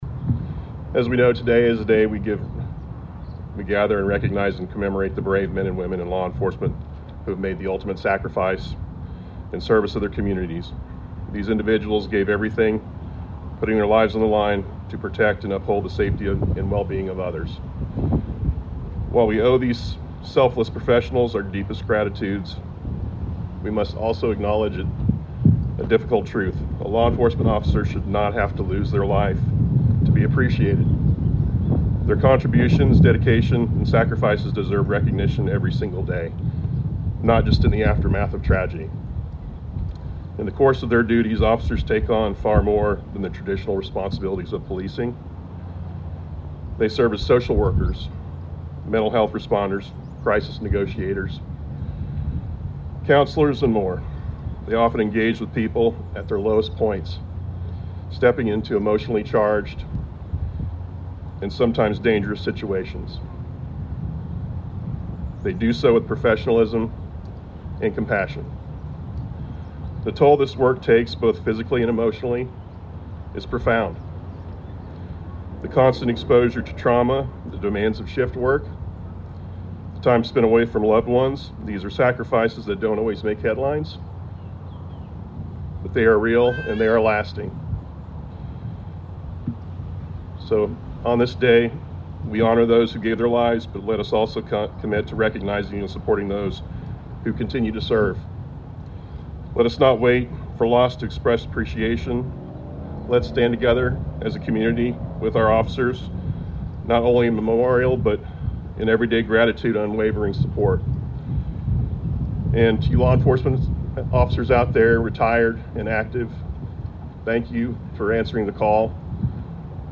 Salinans gathered on a breezy Spring morning Thursday to honor and remember local police, sheriff, and highway patrol troopers who have died in the line of duty. A Peace Officer Memorial Day event was held at Jerry Ivey Park.